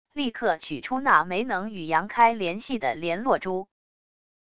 立刻取出那枚能与杨开联系的联络珠wav音频生成系统WAV Audio Player